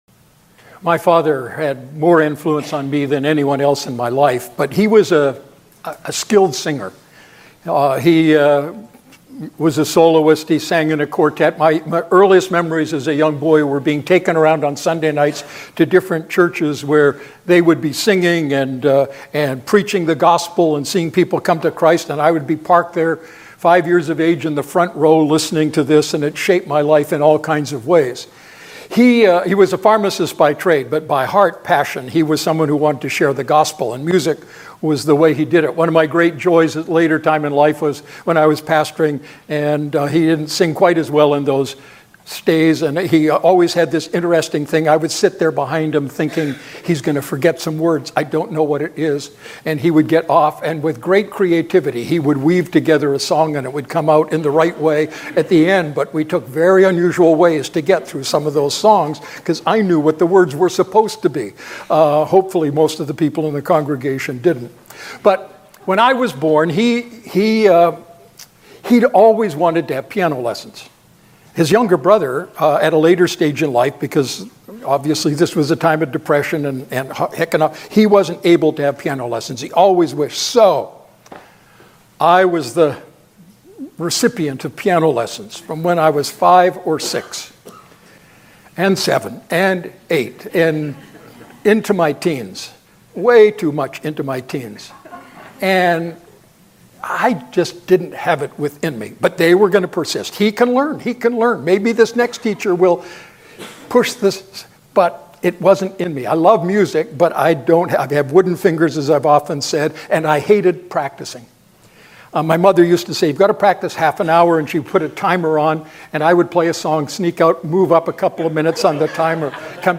Standalone Sermon